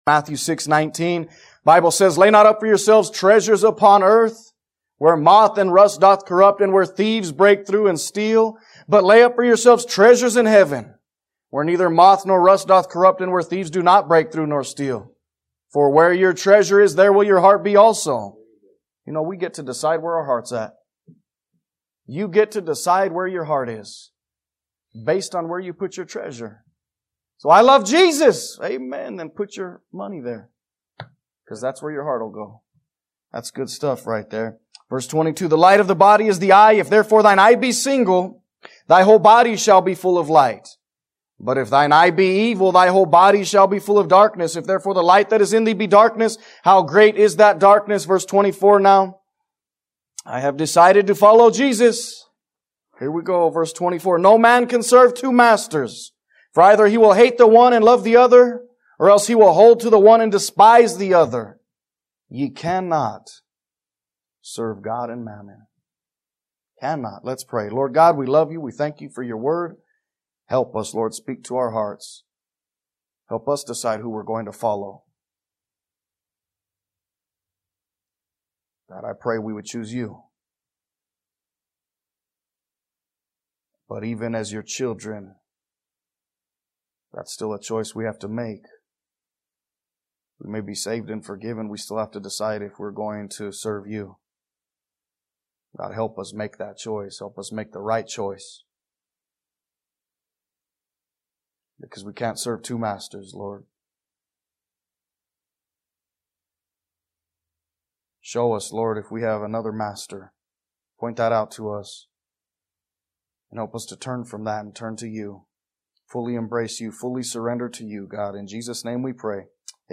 A message from the series "What is a Disciple?."